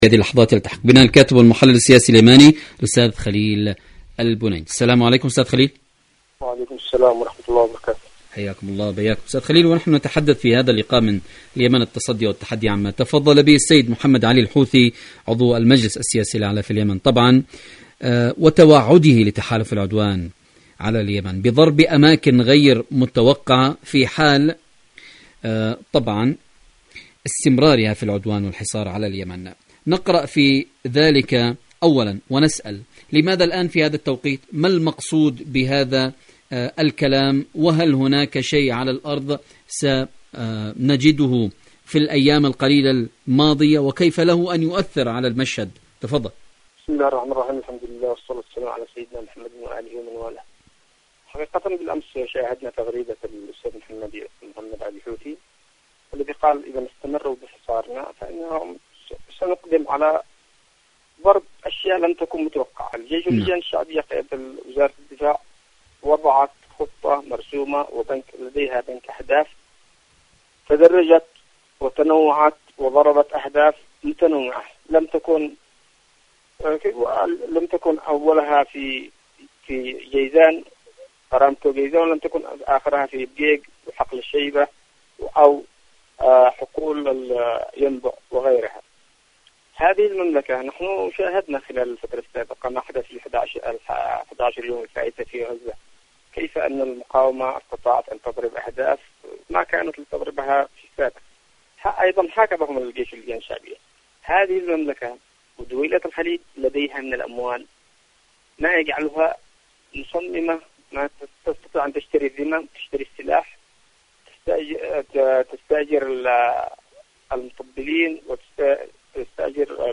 إذاعة طهران-اليمن التصدي والتحدي: مقابلة إذاعية